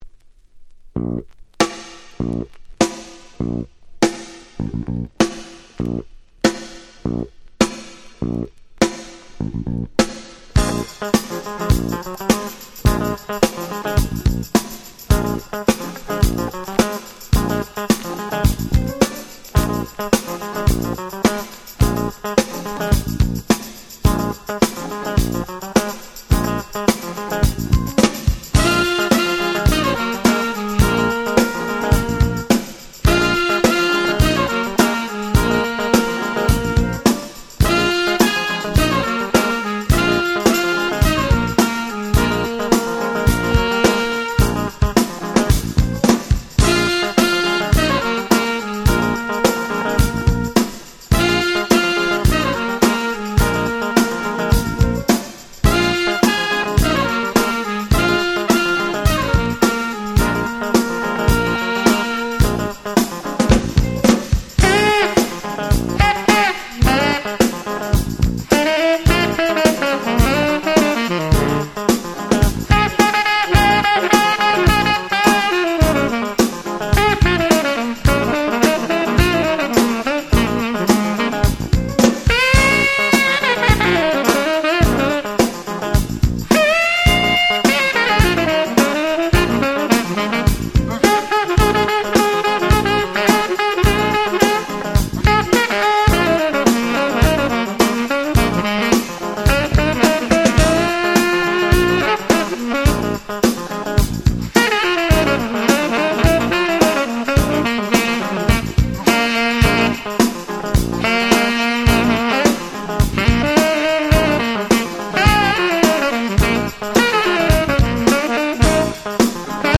91' Very Nice Acid Jazz 12'' !!
アシッドジャズ サックス